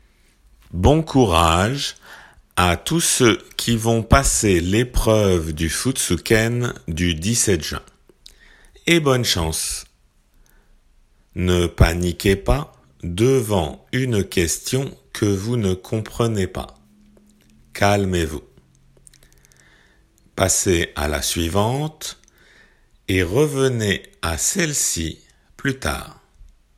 合格者を多く出しているベテラン講師から 受験生に寄せて。